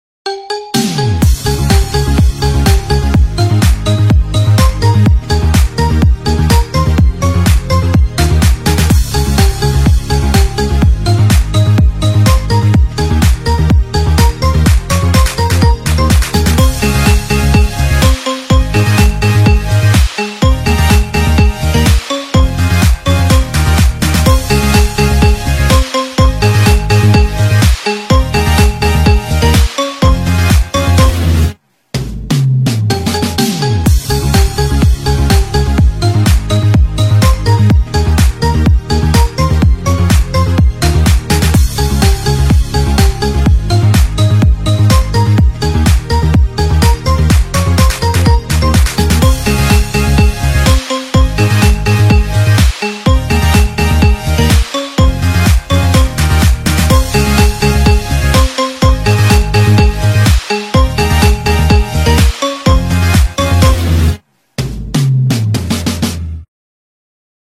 Marimba Ringtone